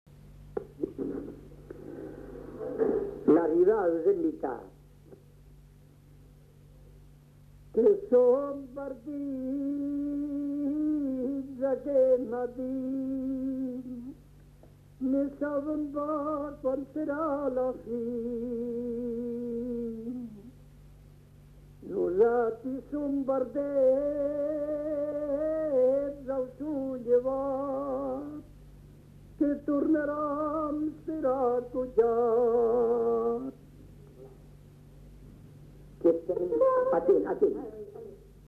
[Brocas. Groupe folklorique] (interprète)
Genre : chant
Effectif : 1
Type de voix : voix d'homme
Production du son : chanté